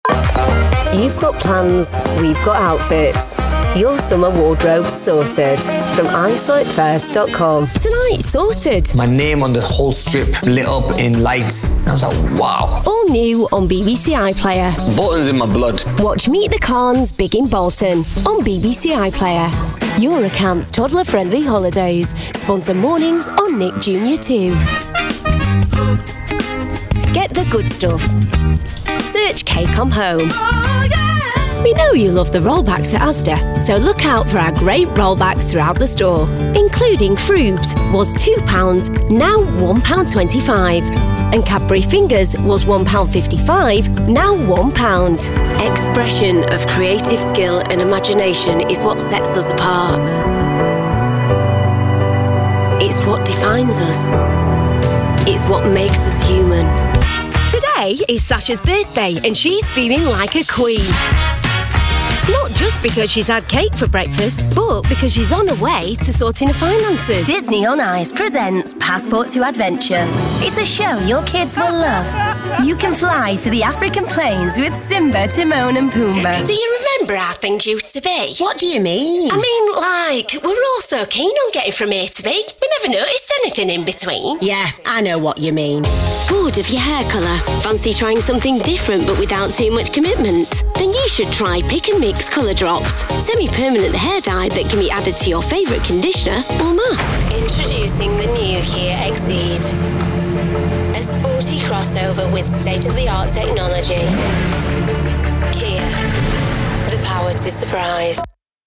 Inglés (Británico)
Comercial, Natural, Travieso, Cool, Cálida
Comercial